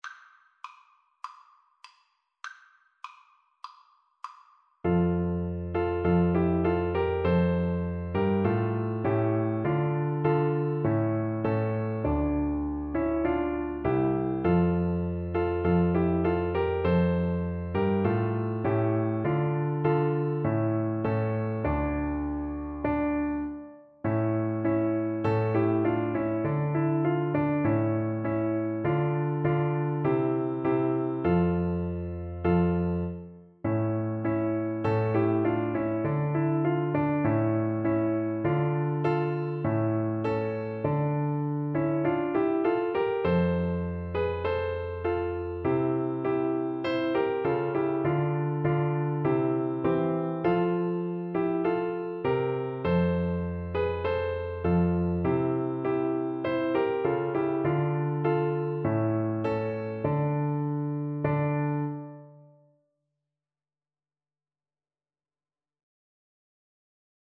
4/4 (View more 4/4 Music)
D4-C5